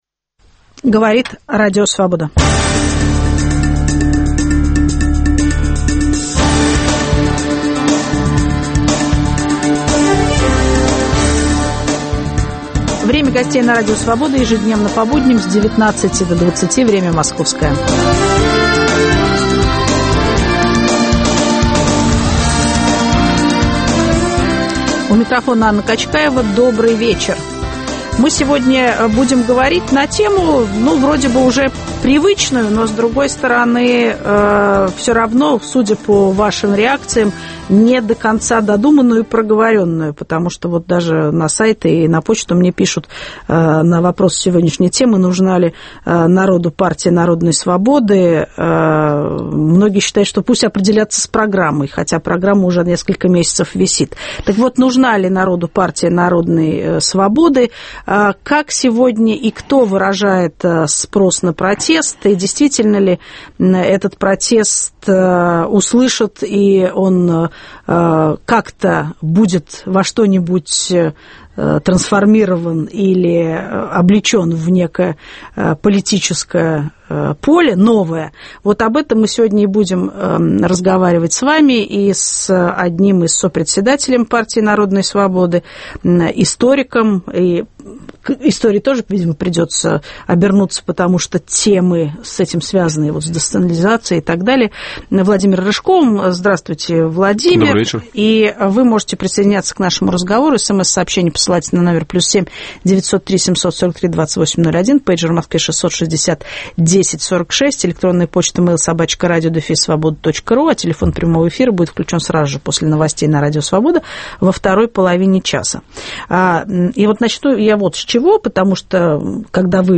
Нужна ли народу Партия Народной Свободы? В студии историк и сопредседатель партии Владимир Рыжков.